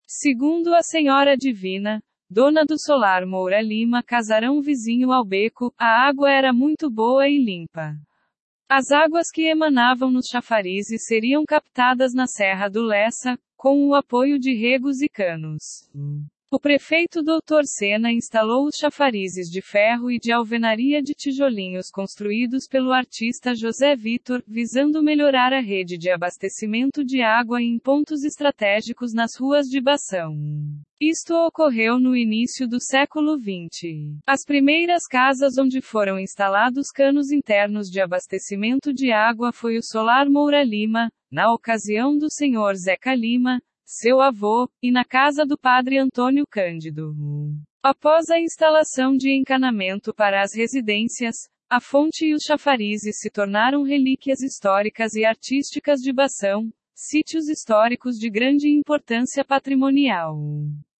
Narrativa